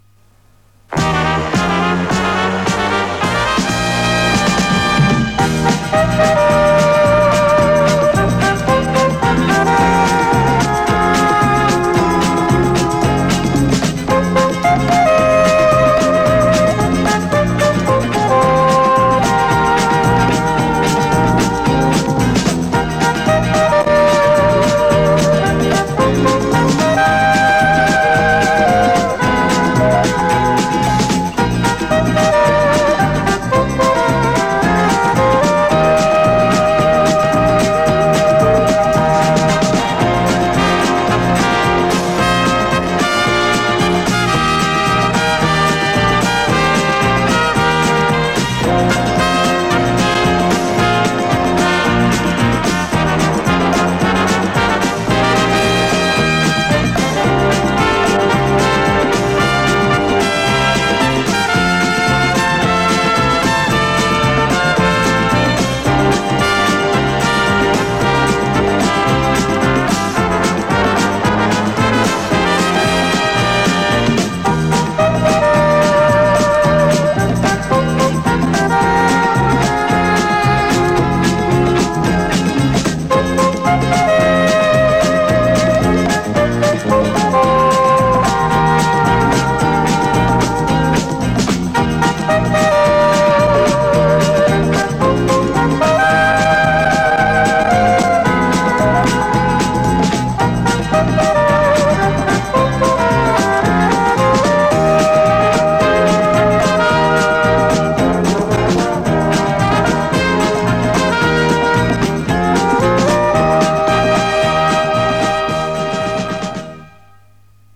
Запись с маяка, 80-е годы.
Похоже на оркестр  ГДР.